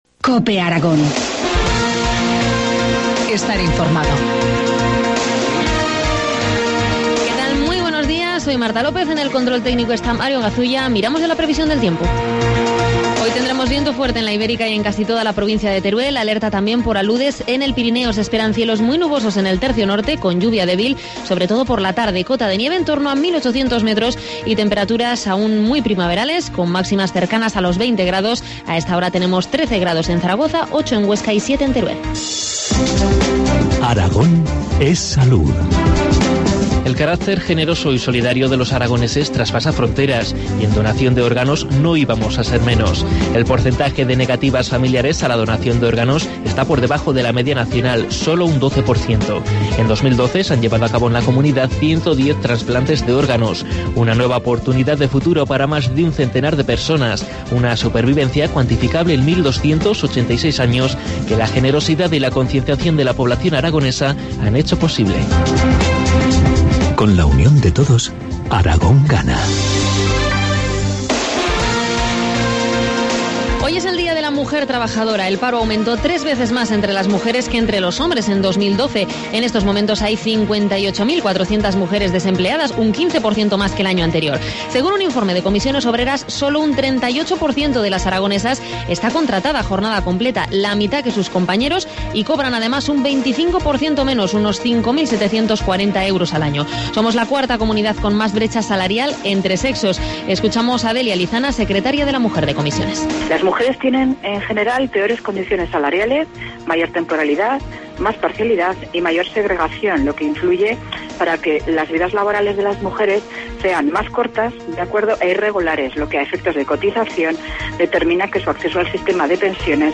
Informativo matinal, viernes 8 de marzo, 8.25 horas